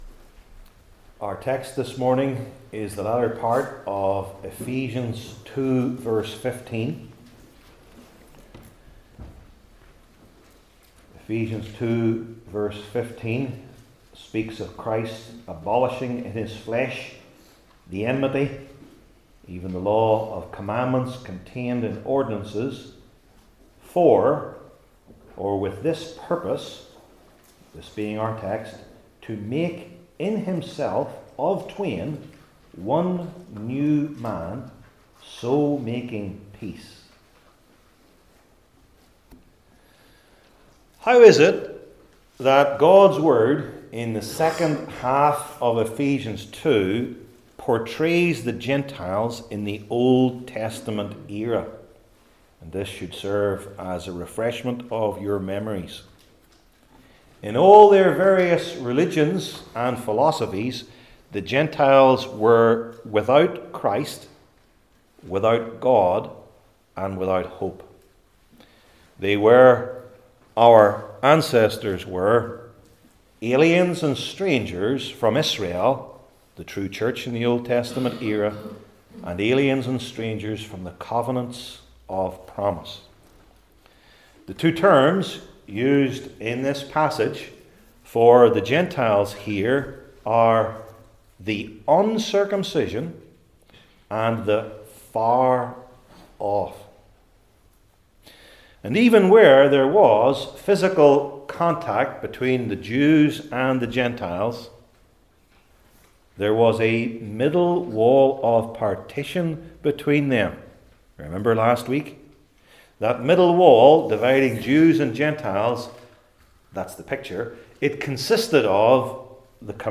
New Testament Sermon Series I. What It Is II.